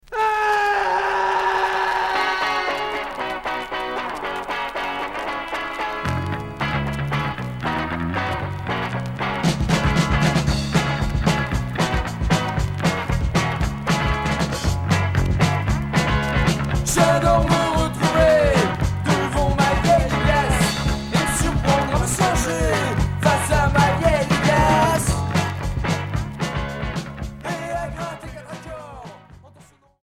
Rock Punk Premier 45t retour à l'accueil